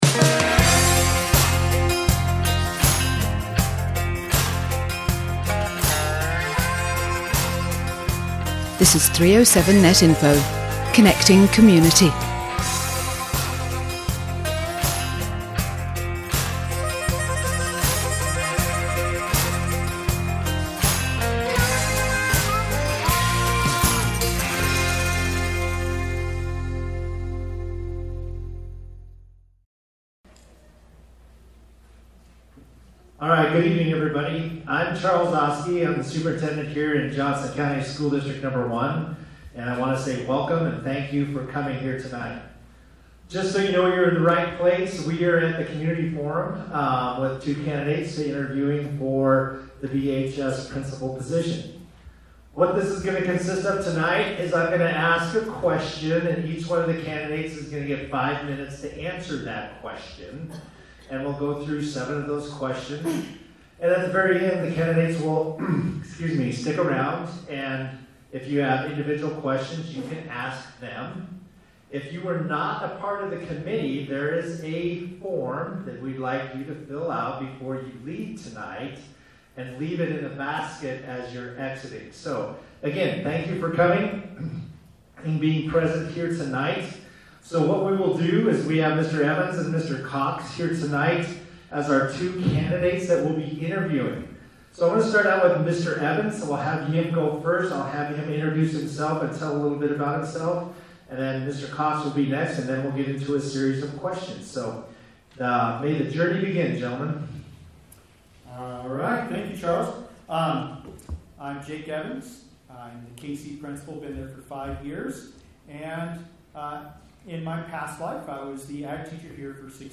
Each candidate will be asked the same set of questions and will have up to five minutes to respond to each. Candidates will take turns answering in an alternating order